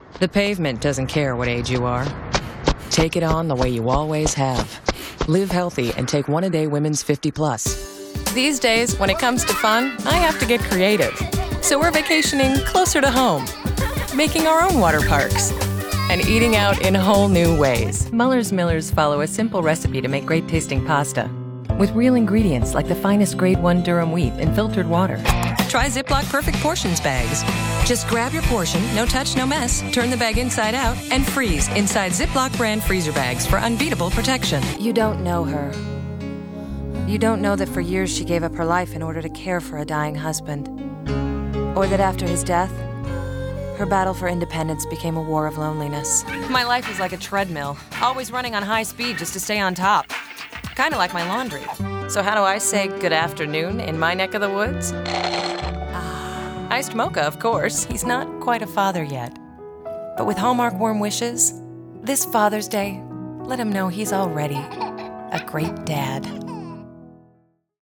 Voiceover : Political : Women